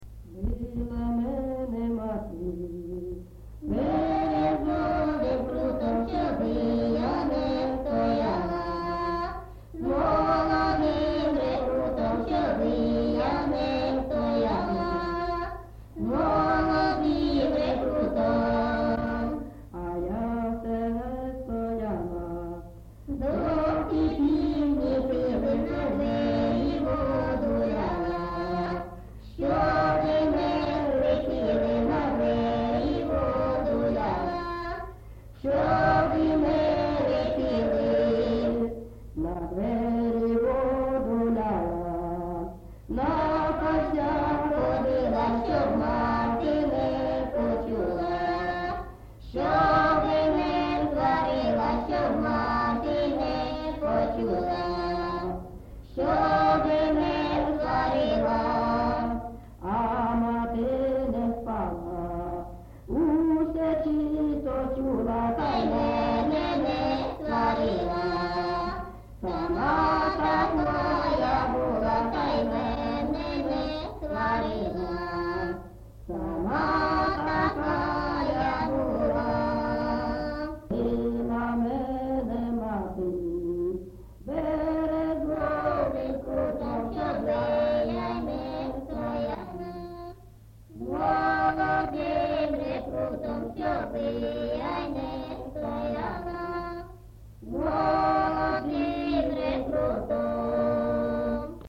Місце записус. Золотарівка, Сіверськодонецький район, Луганська обл., Україна, Слобожанщина